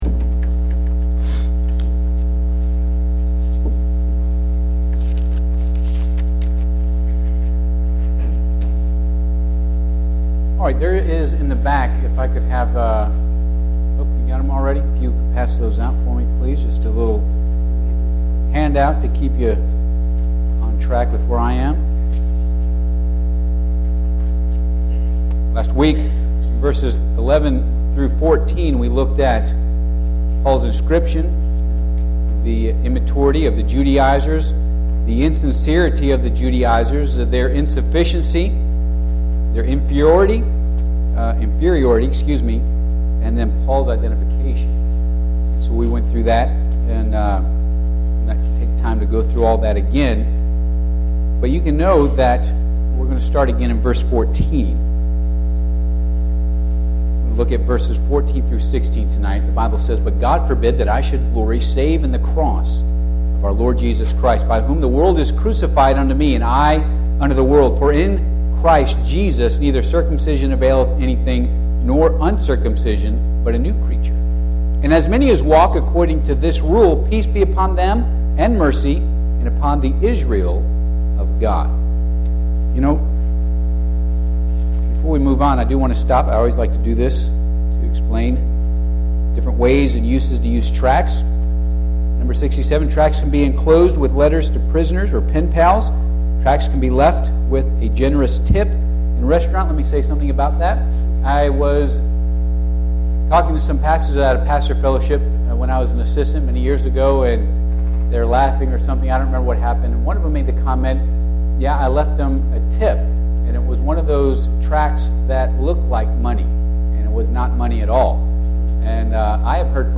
Galatians 6:14-16 Service Type: Midweek Service Bible Text